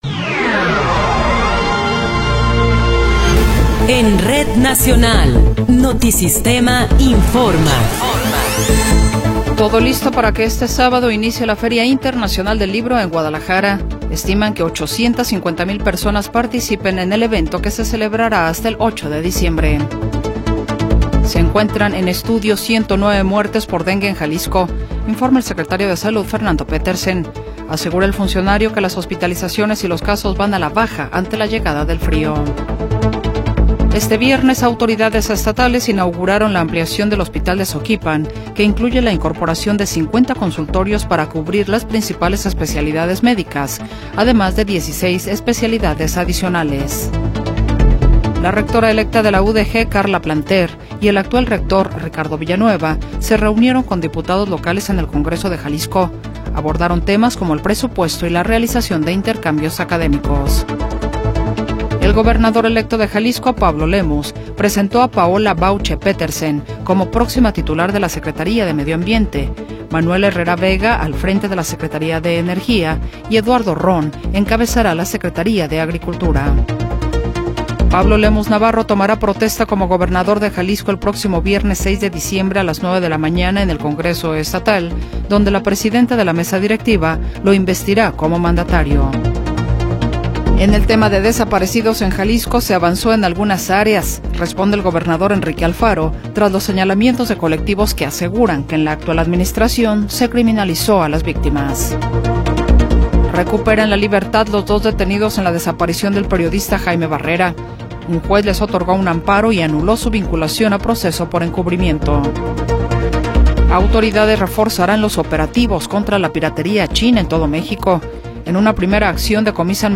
Noticiero 21 hrs. – 29 de Noviembre de 2024
Resumen informativo Notisistema, la mejor y más completa información cada hora en la hora.